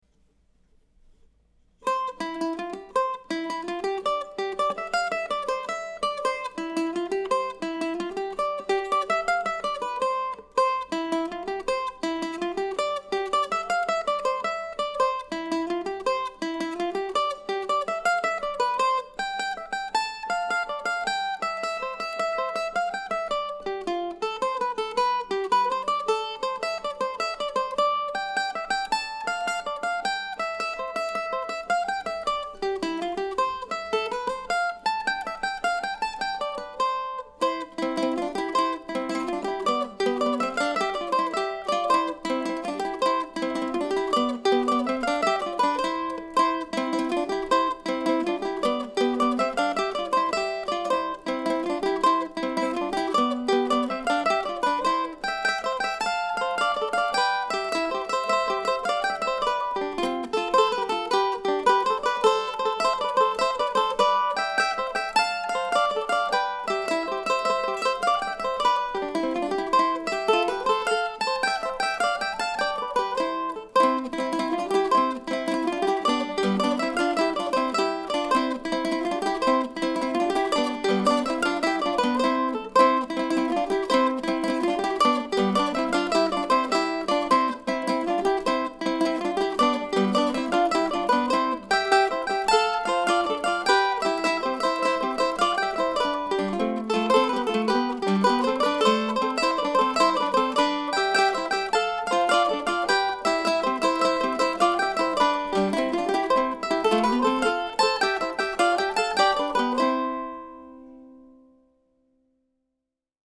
There are three tracks, first melody, then harmony, then the melody played an octave down during the third time through the tune.